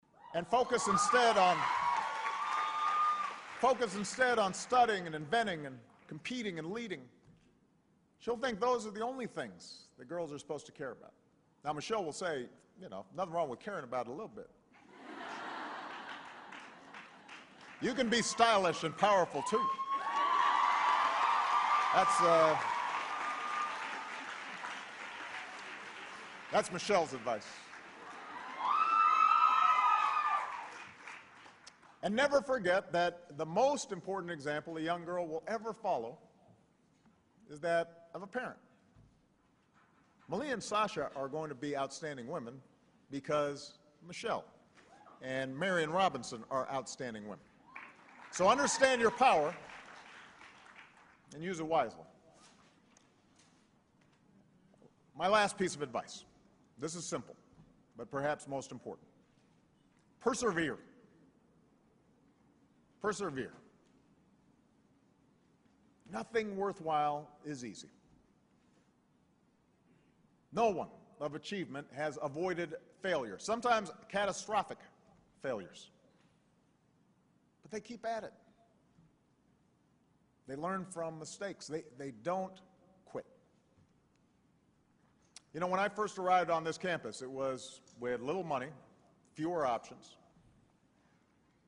公众人物毕业演讲第396期:奥巴马2012年哥伦比亚大学毕业演讲(14) 听力文件下载—在线英语听力室